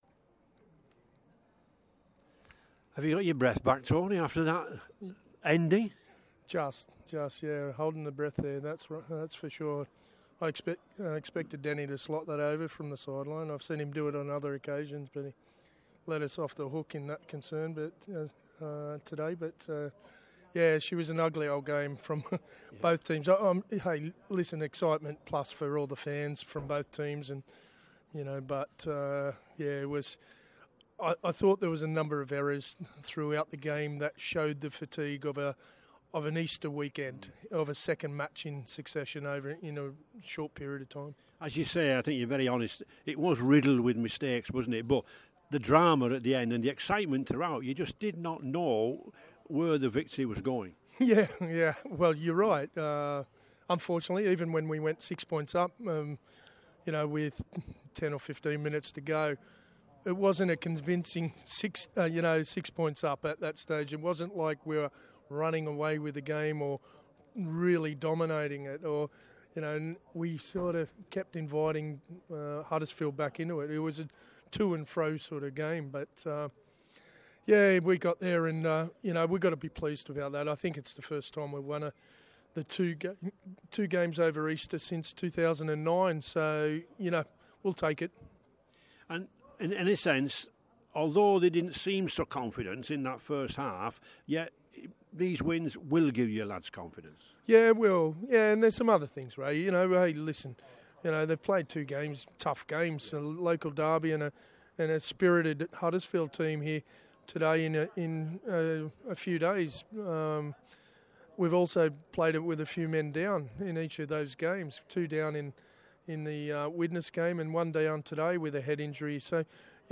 Warrington Wolves head coach Tony Smith speaks following win over Huddersfield.